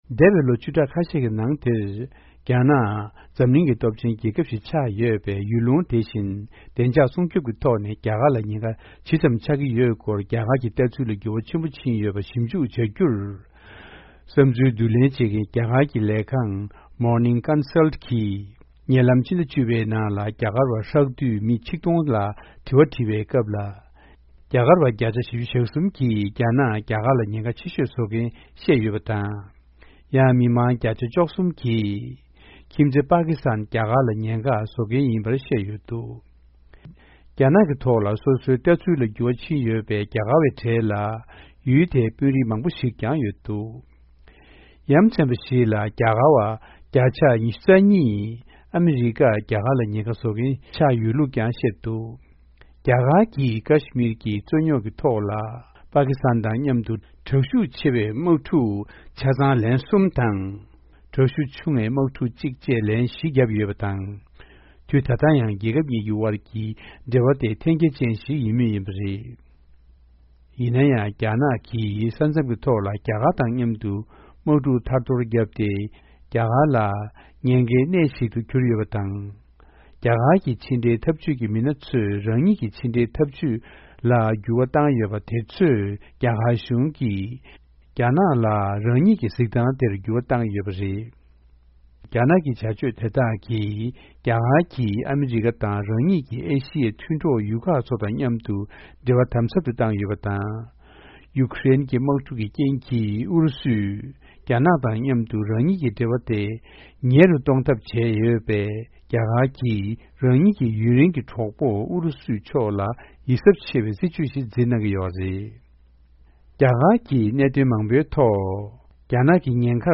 སྙན་སྒྲོན་གནང་བ་ཞིག།